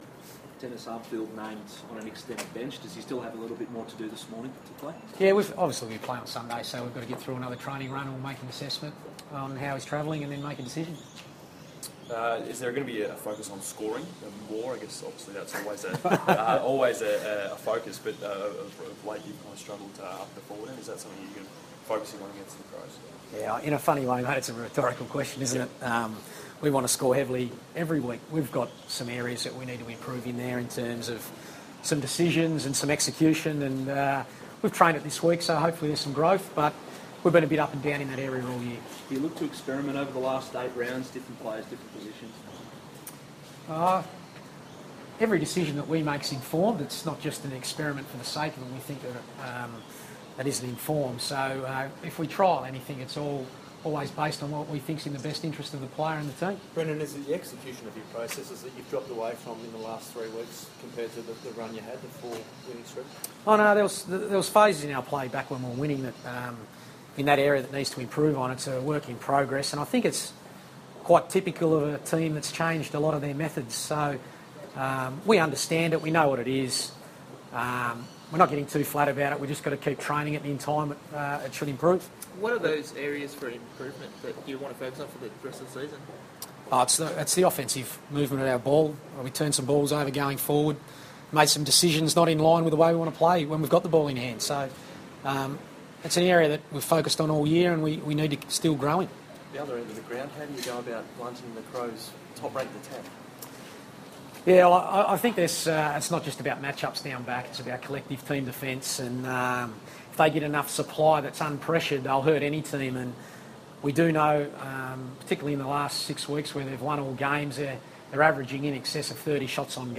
Brendon Bolton press conference - July 8
Carlton coach Brendon Bolton fronts the media ahead of the Blues' Round 16 clash against Adelaide at the MCG.